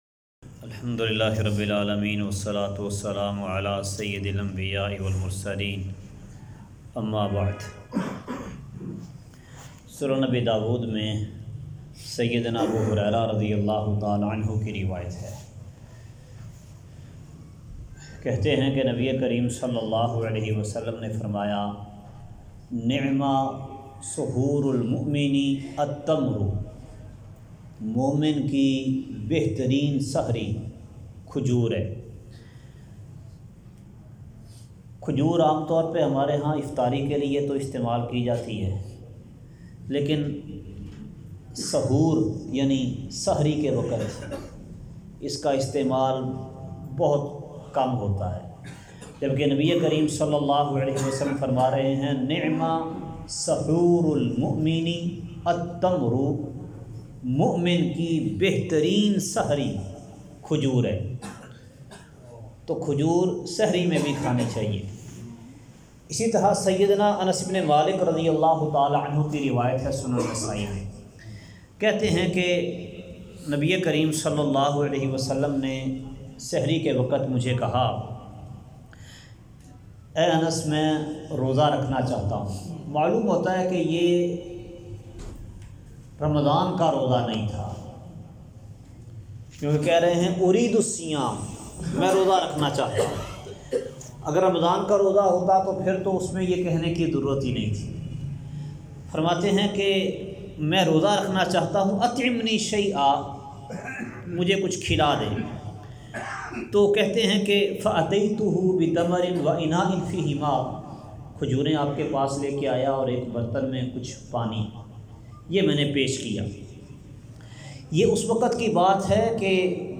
کھجور سے سحری درس کا خلاصہ کھجور مؤمن کے لیے بہترین سحری ہے۔